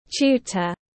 Tutor /ˈtuːtər/